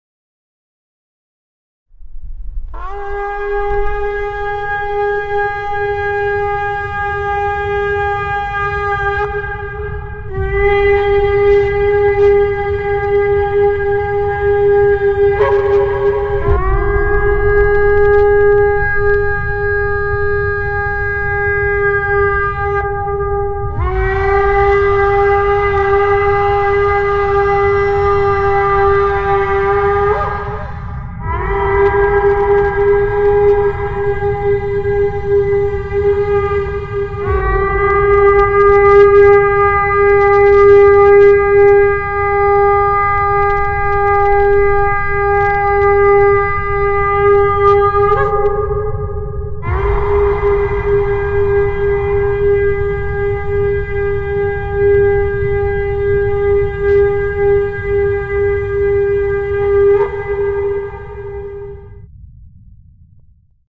Trumpet.mp3
More trumpets!